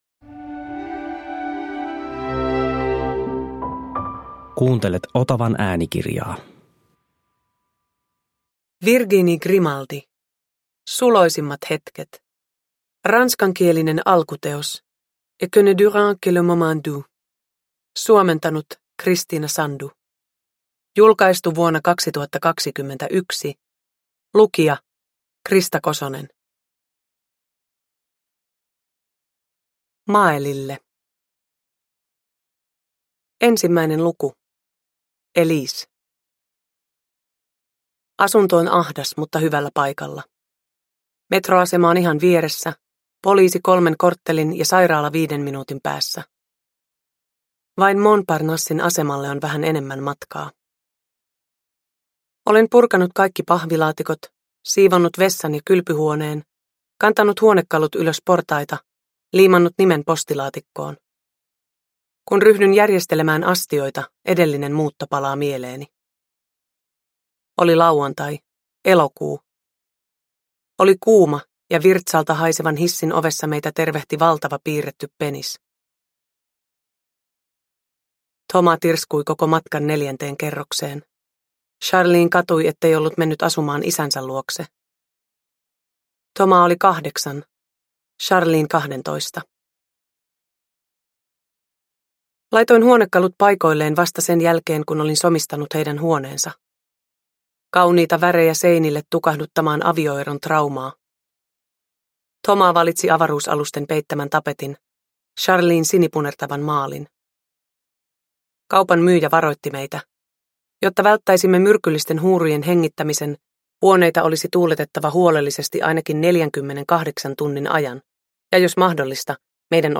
Suloisimmat hetket – Ljudbok – Laddas ner
Uppläsare: Krista Kosonen